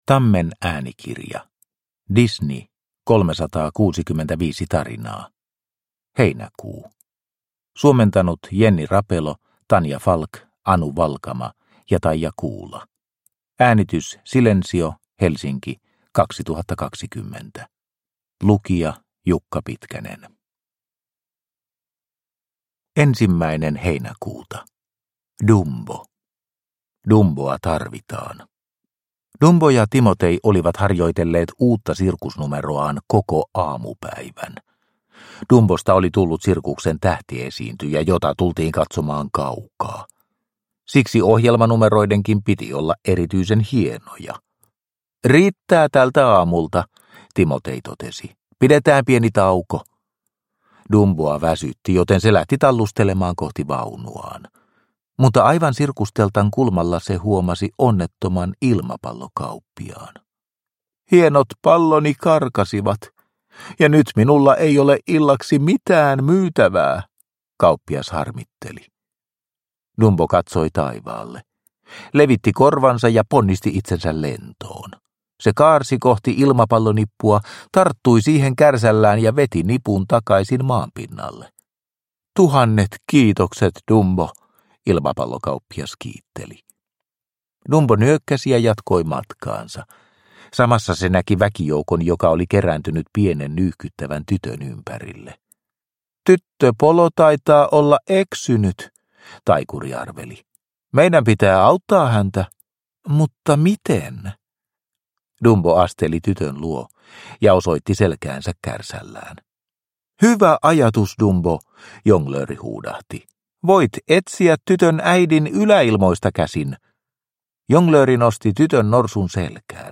Disney 365 tarinaa, Heinäkuu – Ljudbok – Laddas ner